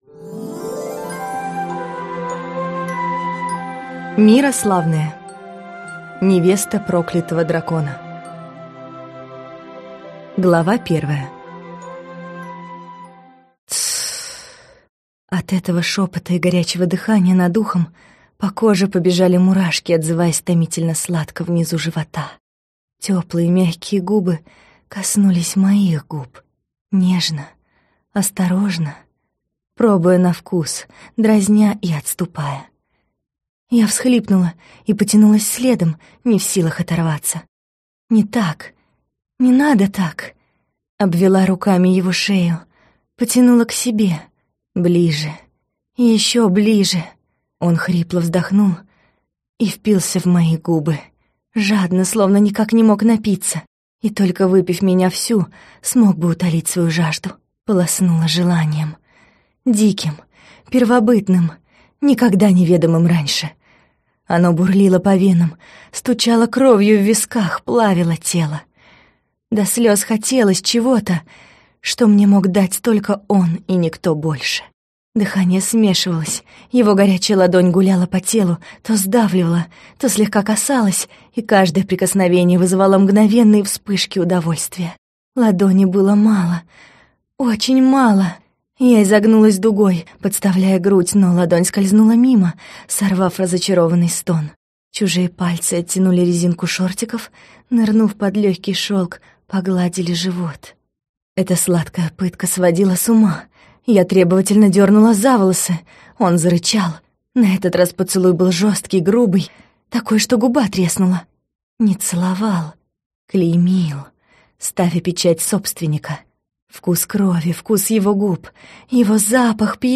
Аудиокнига Невеста проклятого дракона | Библиотека аудиокниг